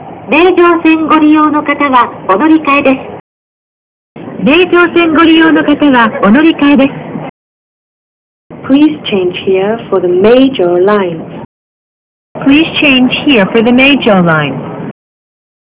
6050&N1000車内放送
初めに日本語放送が流れ、その後英語放送が流れます。